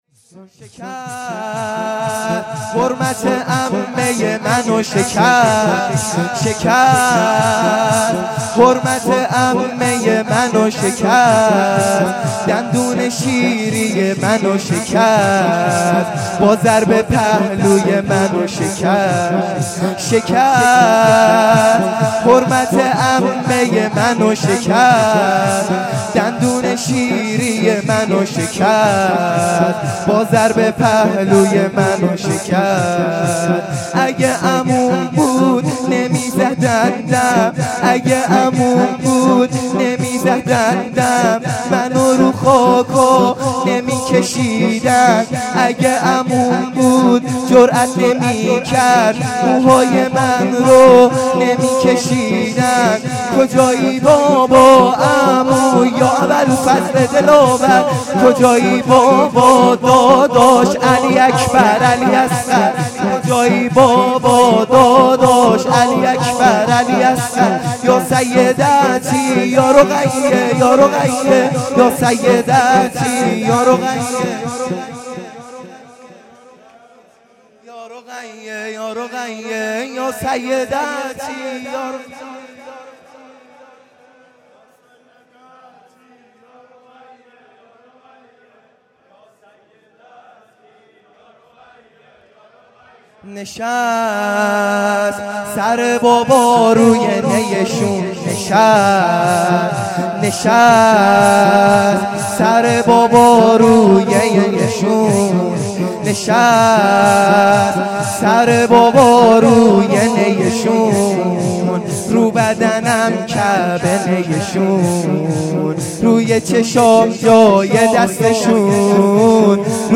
لطمه زنی
شب 23 رمضان المبارک